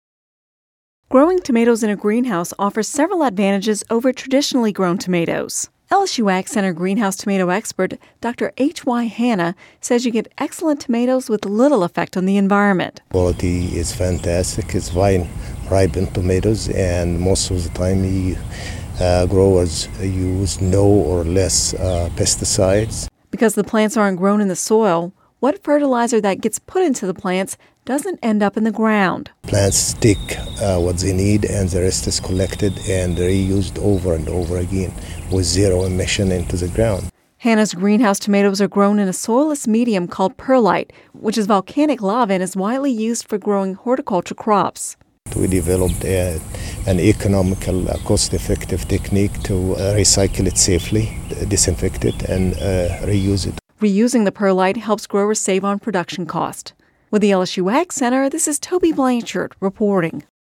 (Radio News 07/12/10) Growing tomatoes in a greenhouse offers several advantages over traditionally-grown tomatoes.